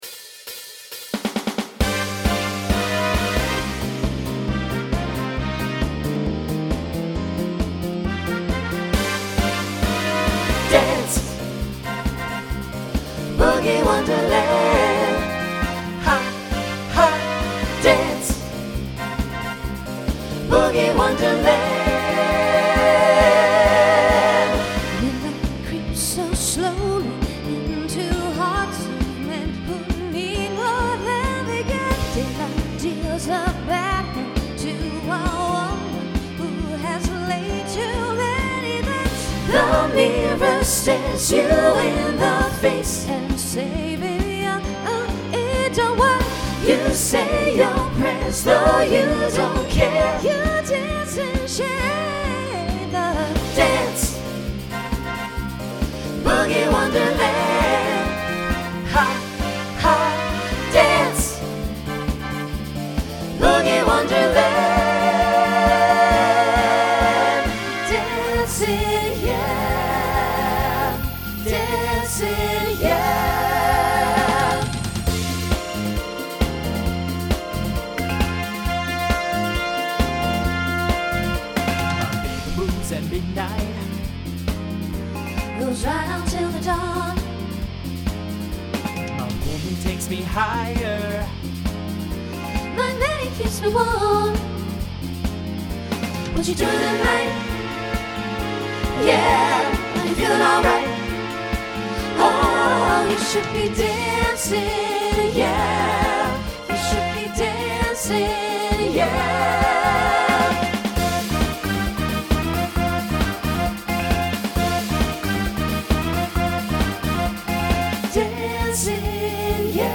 Voicing SATB Instrumental combo Genre Pop/Dance Decade 1970s